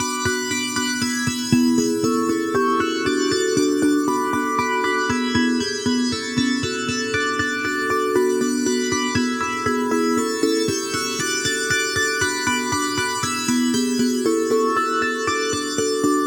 • SC Essentials Key Pop 3 118 bpm.wav
SC_Essentials_Key_Pop_3_118_bpm_Nis_oKi.wav